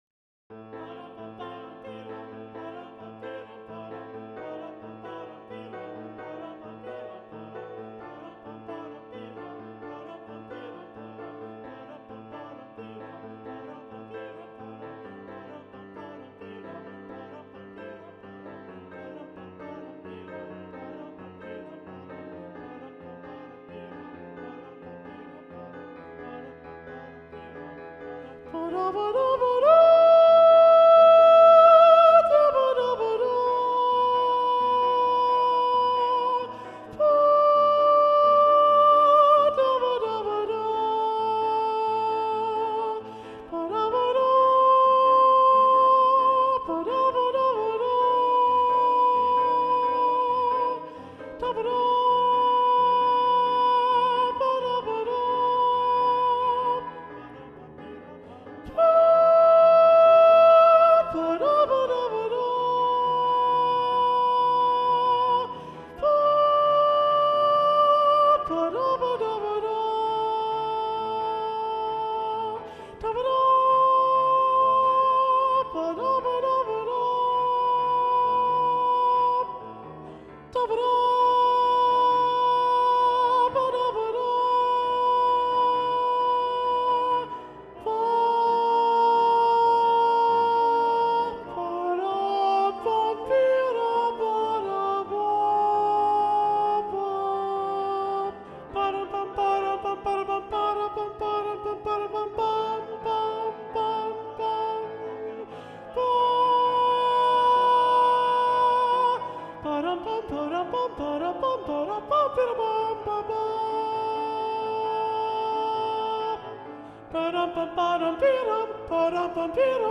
- Œuvre pour chœur à 7 voix mixtes (SSAATTB) + piano
SATB Soprano 1 (chanté)